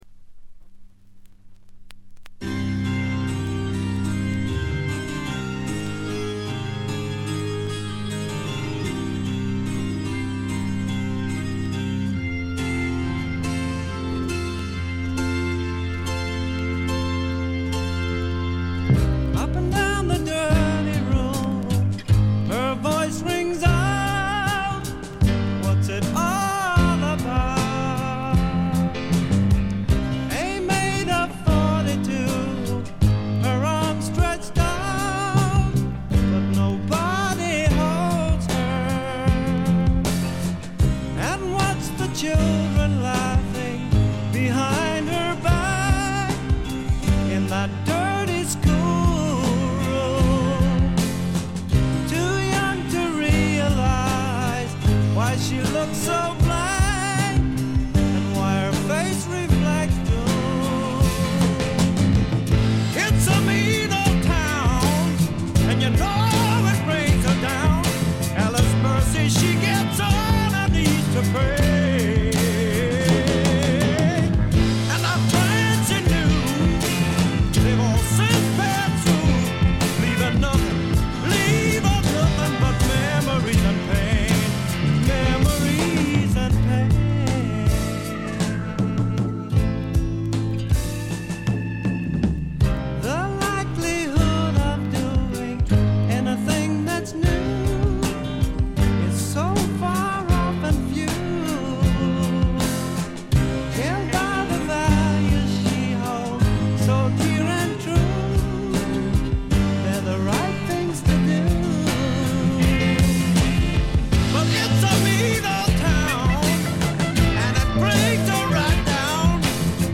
軽微なチリプチ少し。
試聴曲は現品からの取り込み音源です。